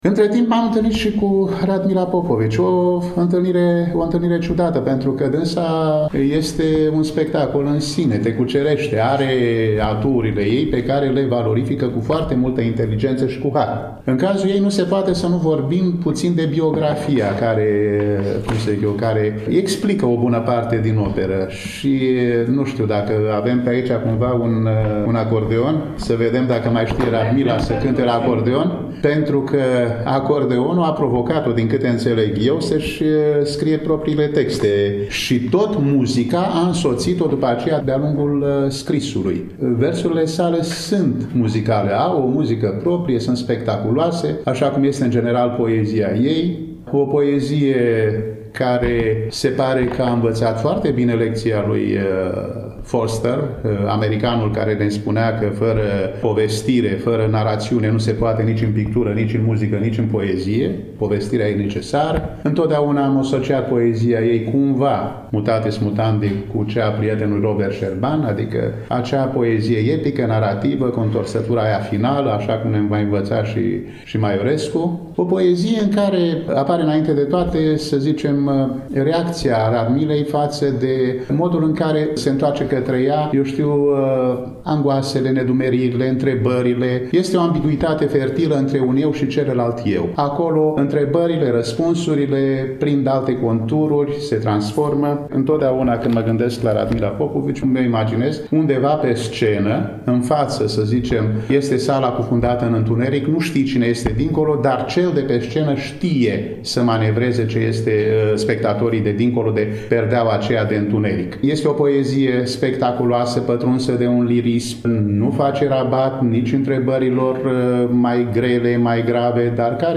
Cărțile au fost prezentate, la Iași, în ziua de joi, 21 noiembrie 2024, începând cu ora 14, în incinta sediului Editurii Junimea din Parcul Copou